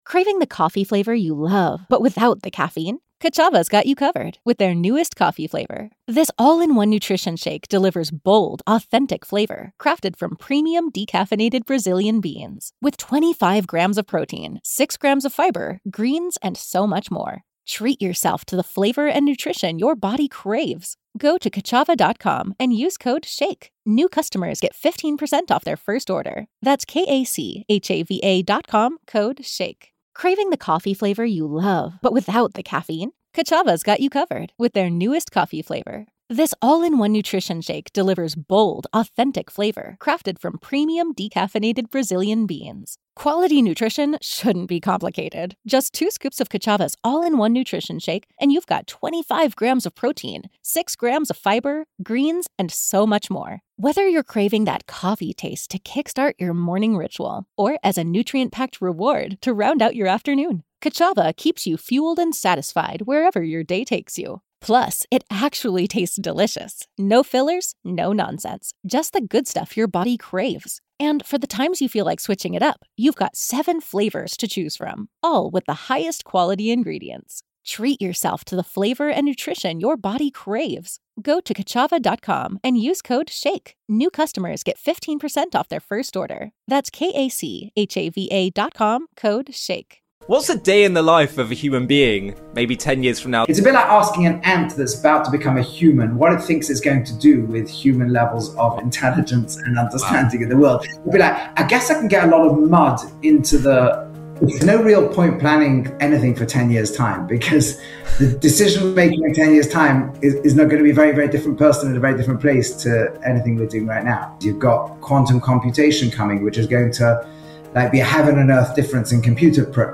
A fascinating discussion